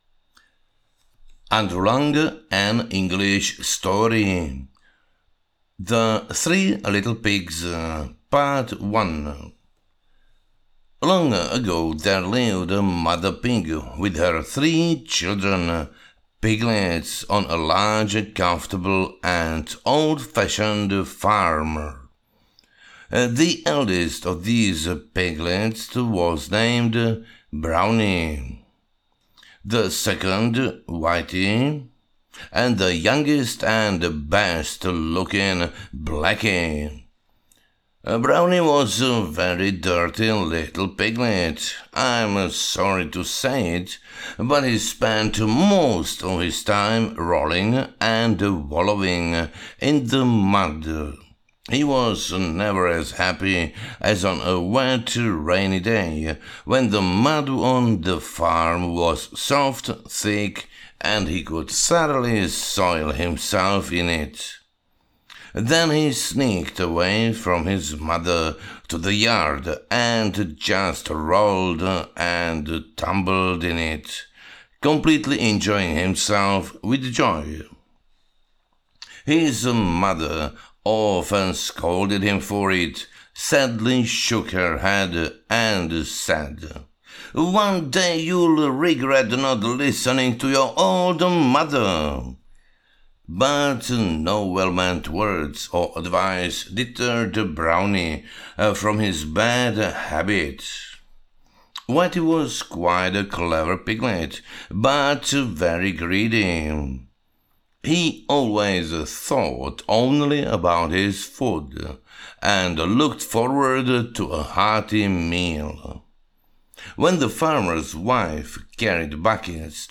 Ukázka z knihy
Ten člověk co to namluvil mluví horší angličtinou než moje děti